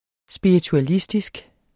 Udtale [ sbiɐ̯ituaˈlisdisg ]